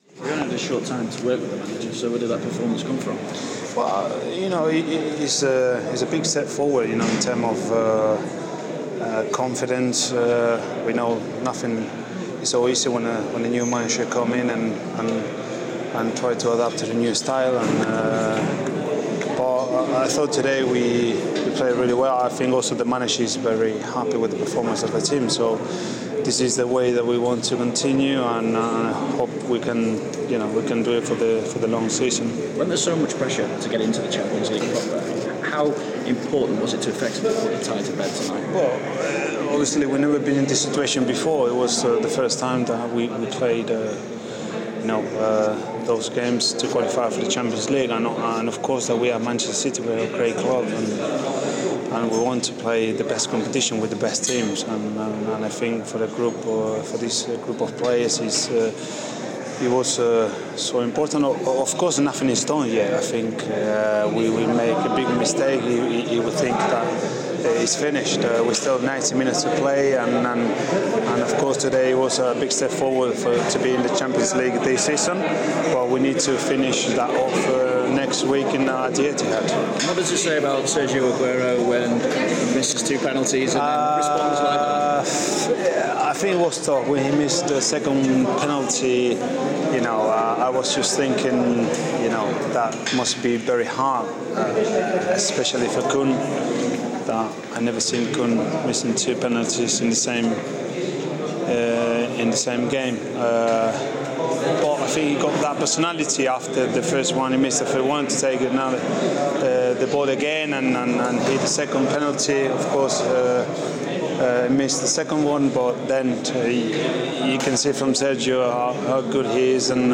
Man City right back Pablo Zabaleta reacts to the 5-0 win over Steaua Bucharest, and discusses Joe Hart's future at the club.